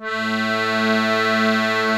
A2 ACCORDI-R.wav